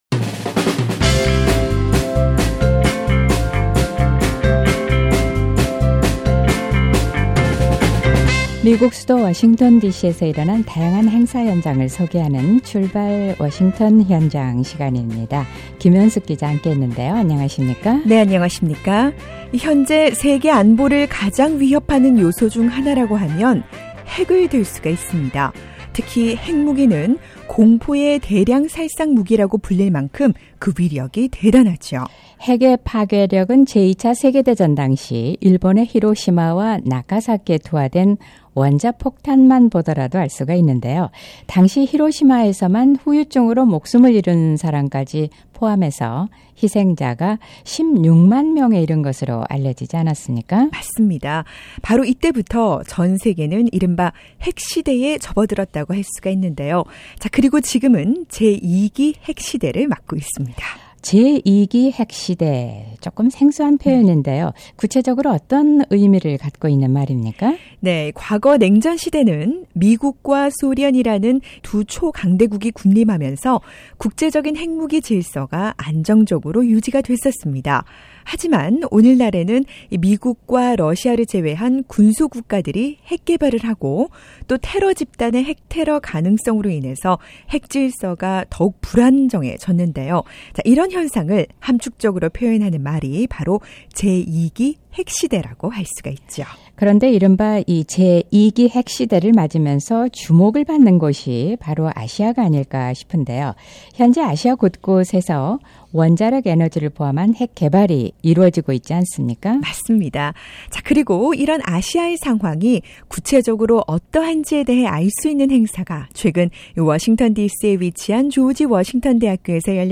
그리고 왜 전 세계는 유독 북한의 핵 개발을 우려하고 있는 걸까요? 이에 대한 해답을 제시한 ‘제2기 핵시대를 맞이한 아시아’ 연구 보고서 발표해 현장으로 출발해 봅니다.